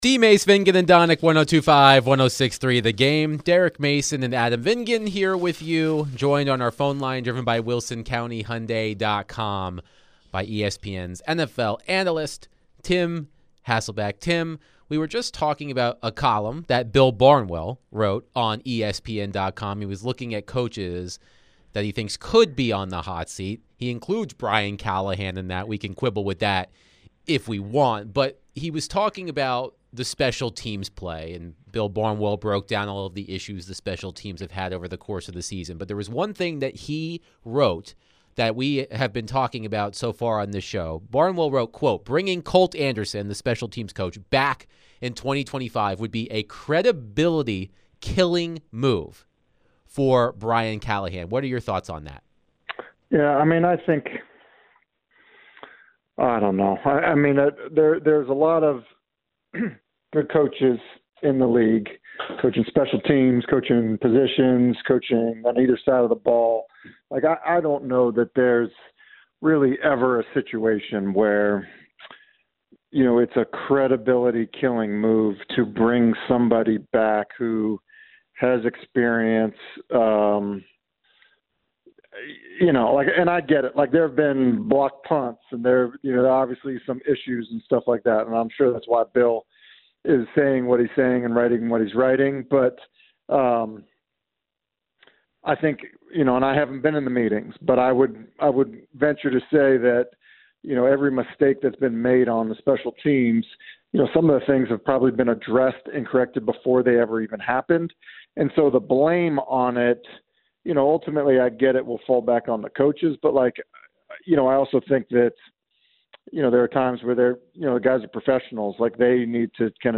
ESPN NFL Analyst Tim Hasselbeck joined the show to share his thoughts on the Titans' loss to the Commanders on Sunday. What does he think about the potential of Brian Callahan being on the hot seat?